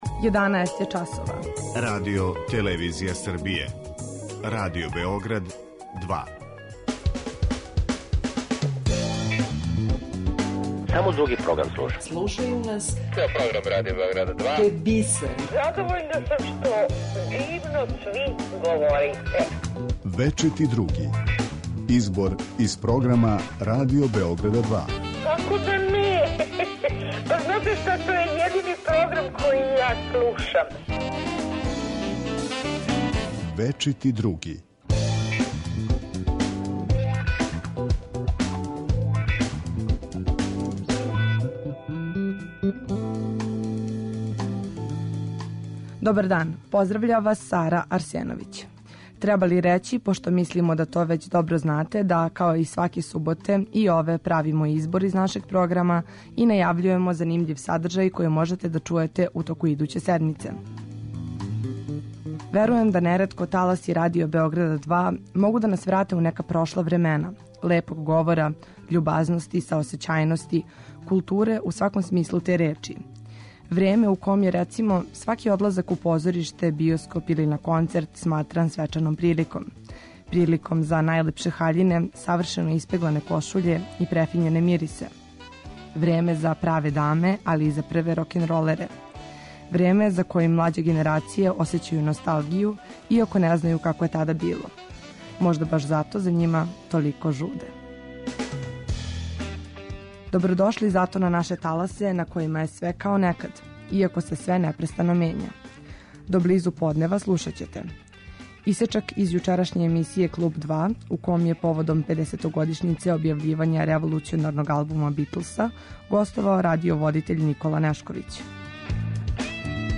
У емисији „Вечити други“ слушаоци ће моћи да чују избор најзанимљивијих садржаја емитованих на програму Радио Београда 2 током претходне и најаву онога што ће бити на програму идуће седмице.